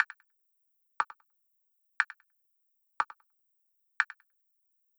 Sound: Clicking Clock
ticking-clock.wav